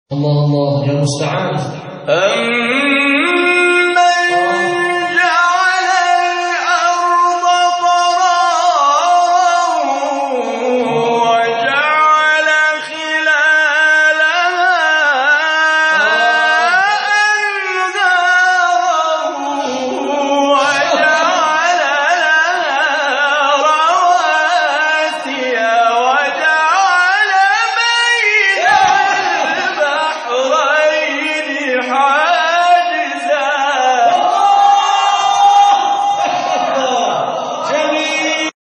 گروه شبکه اجتماعی: مقاطعی از تلاوت قاریان ممتاز و بین‌المللی کشور که به‌تازگی در شبکه اجتماعی تلگرام منتشر شده است، می‌شنوید.